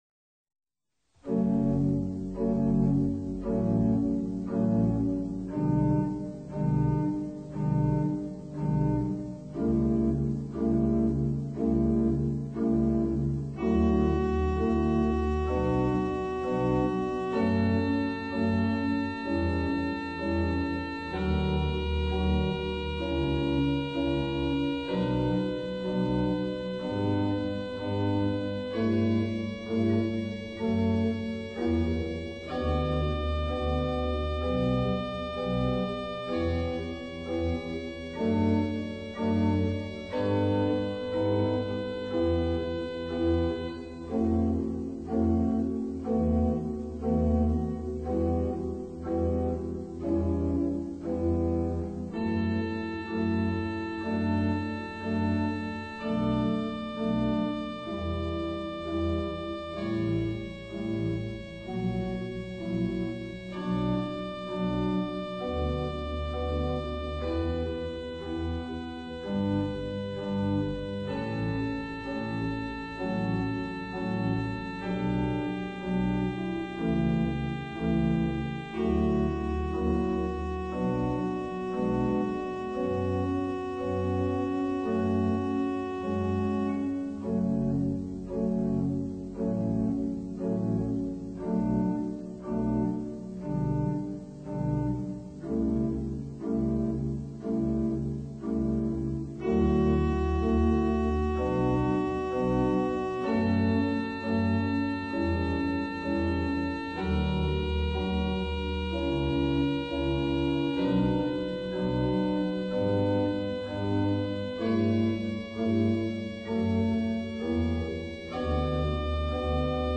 à l'orgue d'Aubusson
choral BWV 721
prise de son analogique